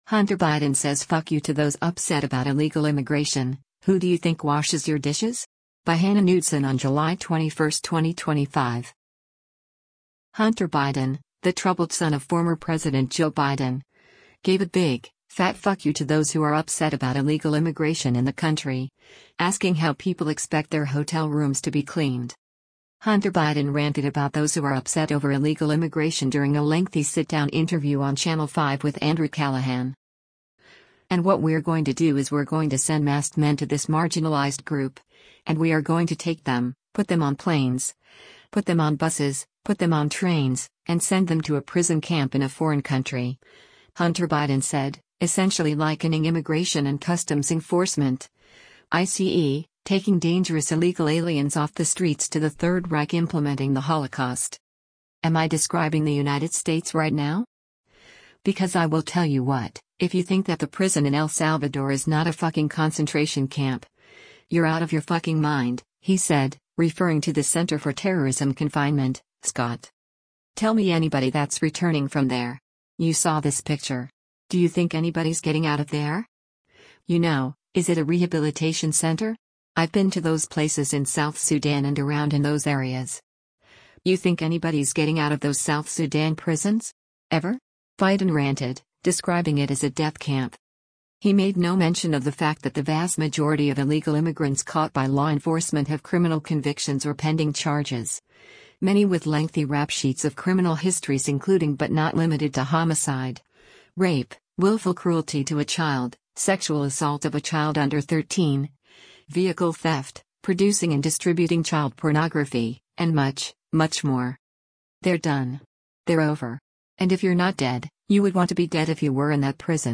Hunter Biden ranted about those who are upset over illegal immigration during a lengthy sit-down interview on “Channel 5 with Andrew Callaghan.”